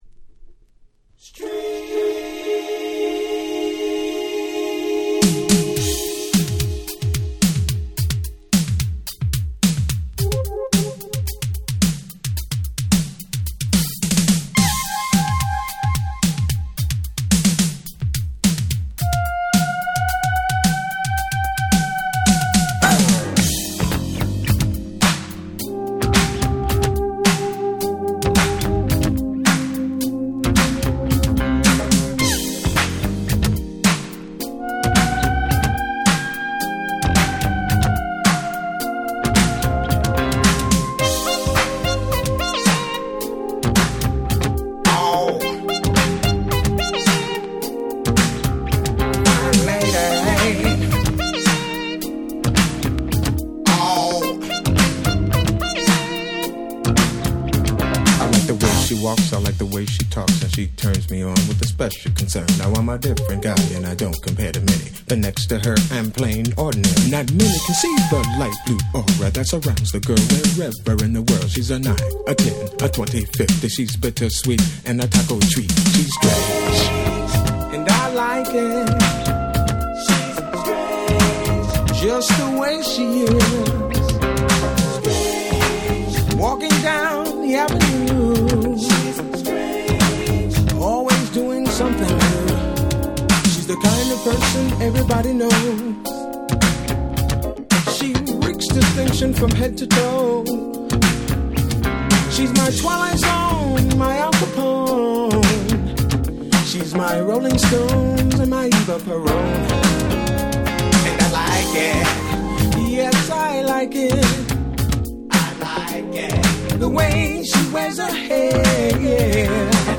86’ Smash Hit Disco/Funk !!